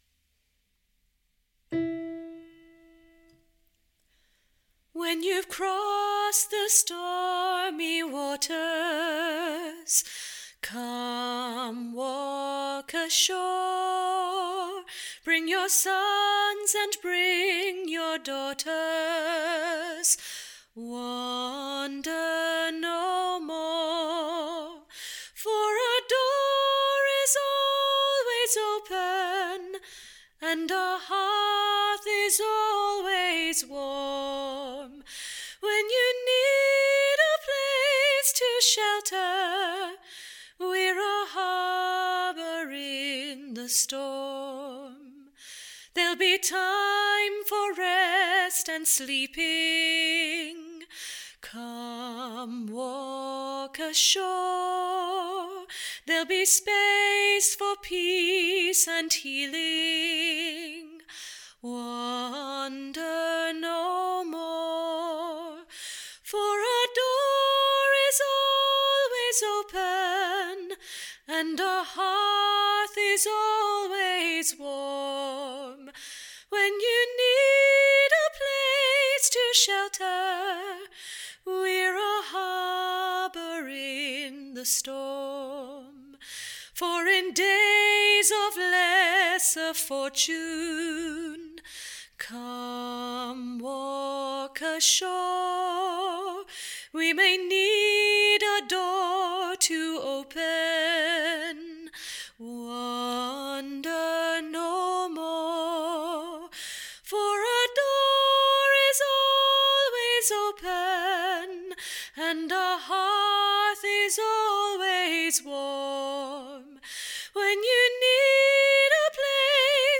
Harbour Soprano